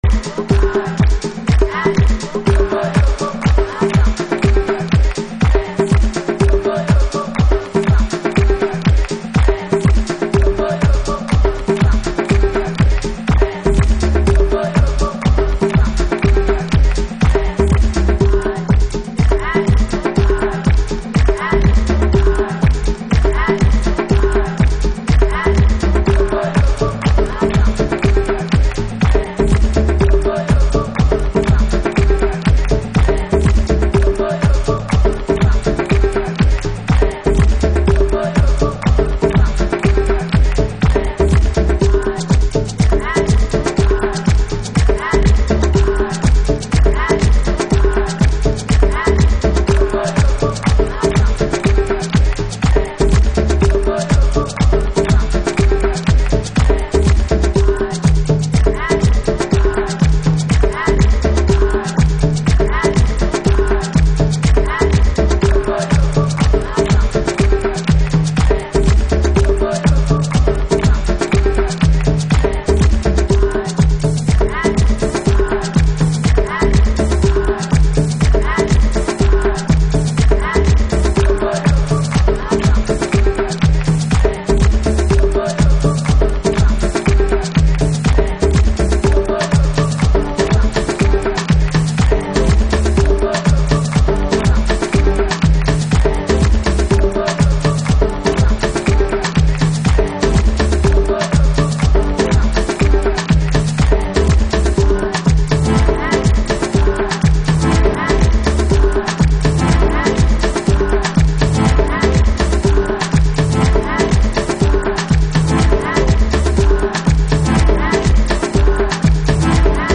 House / Techno
アフロ回帰派のスピリチュアルディープ、ずぶずぶまではいかない都会的なアレンジが煌びやかなグルーヴを作り出しています。